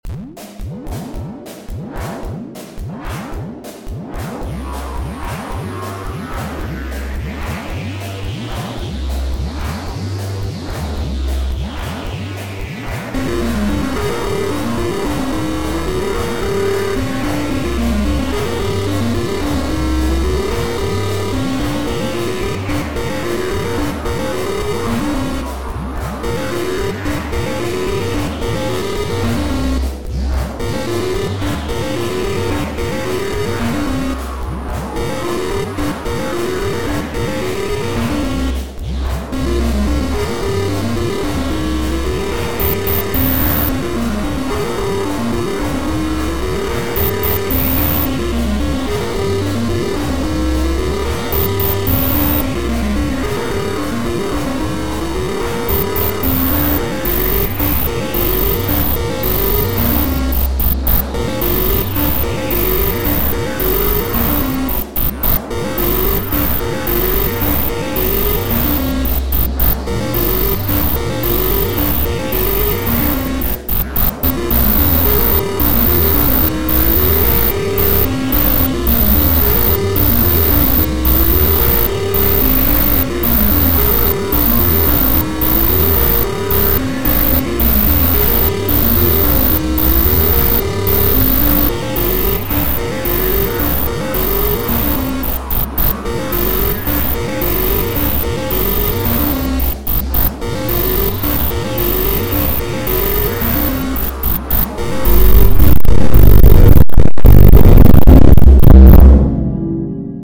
some noisy but rhythmic electronic music